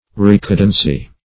recadency.mp3